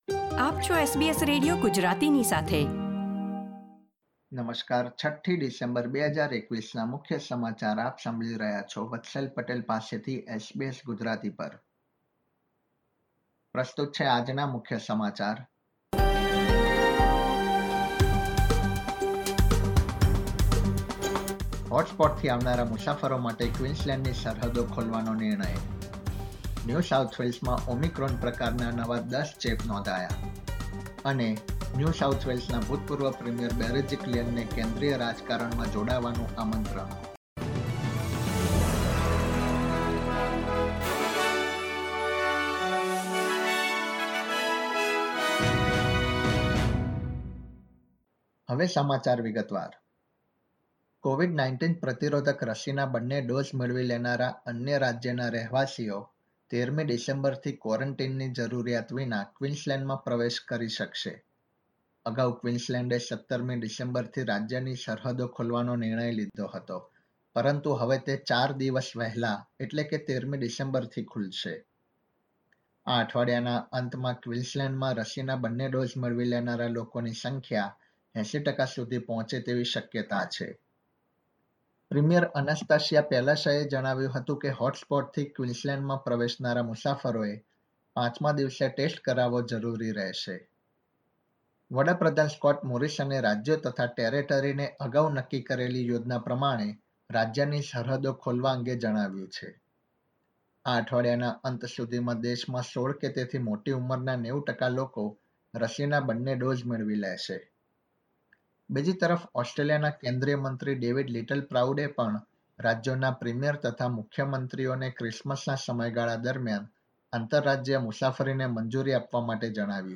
SBS Gujarati News Bulletin 6 December 2021
gujarati_0612_newsbulletin.mp3